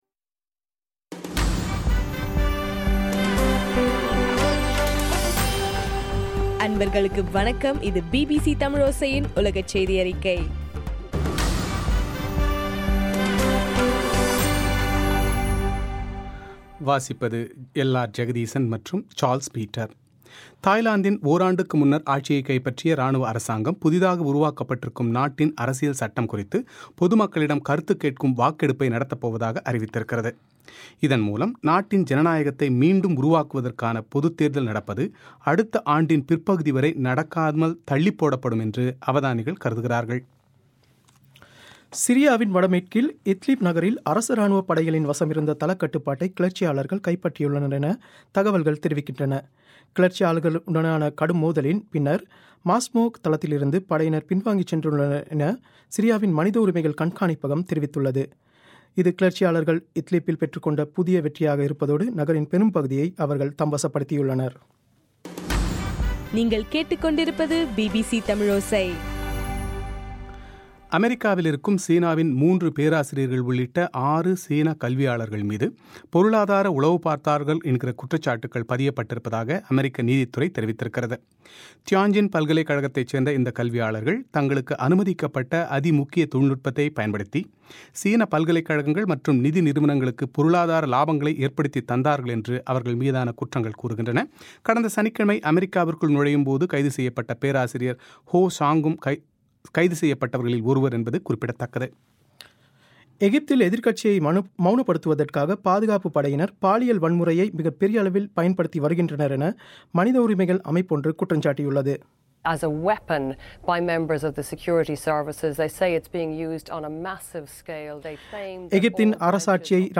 மே 19 பிபிசியின் உலகச் செய்திகள்